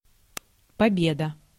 Ääntäminen
Synonyymit виктория выигрыш Ääntäminen : IPA: /pɐ.ˈbʲe.də/ Haettu sana löytyi näillä lähdekielillä: venäjä Käännös Ääninäyte Substantiivit 1. victory US 2. triumph US 3. win US Translitterointi: pobeda.